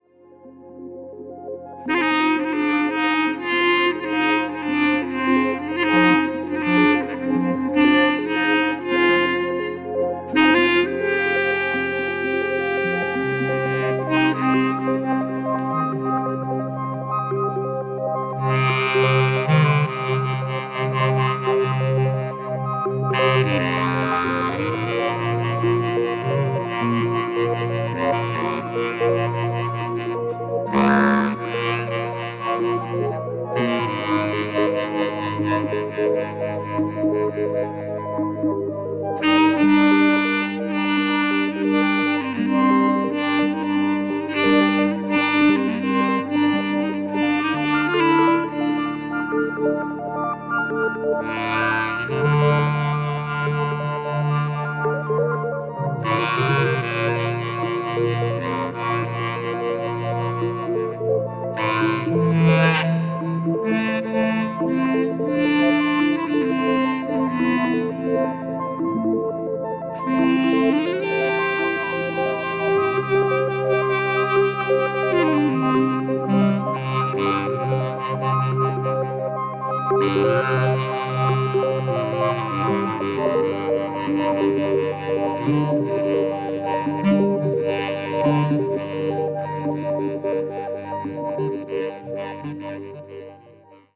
幻想的なアルバムです！！！
Drums, Congas, Electric Piano
Saxophone, Clarinet, Synthesizer